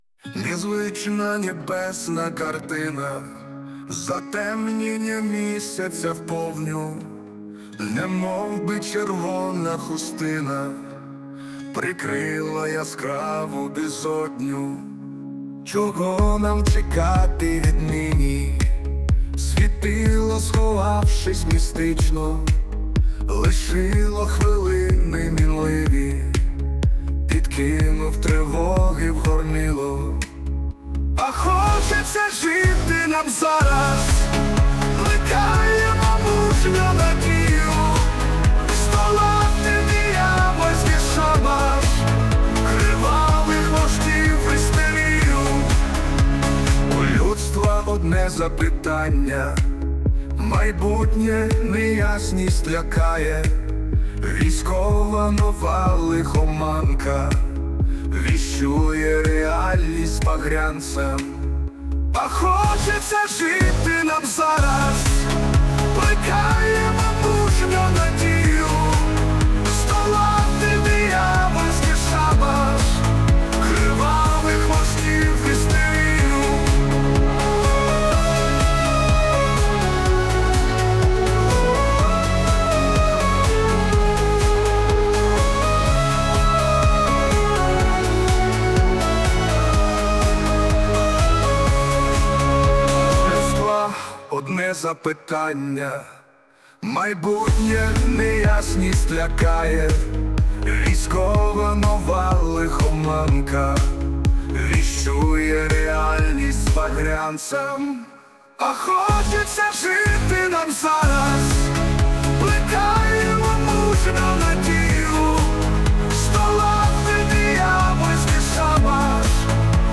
Музична композиція створена за допомогою ШІ